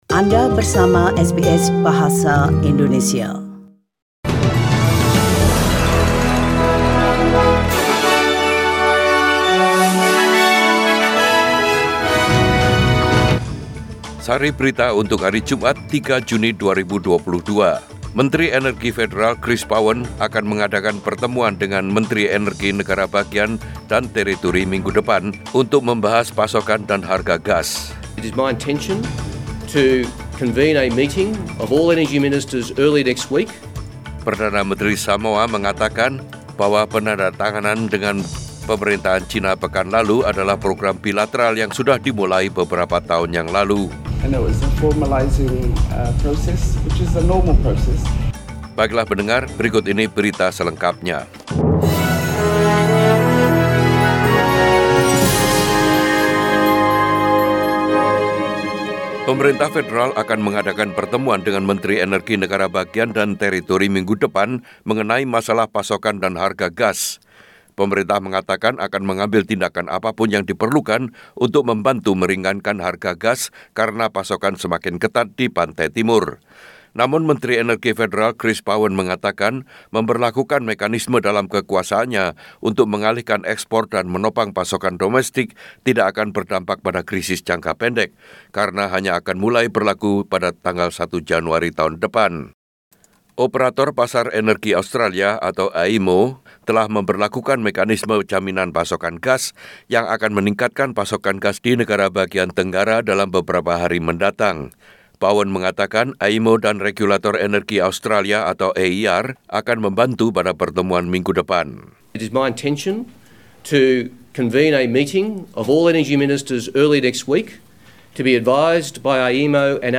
SBS Radio News in Bahasa Indonesia - 3 June 2-22
Warta Berita Radio SBS Program Bahasa Indonesia.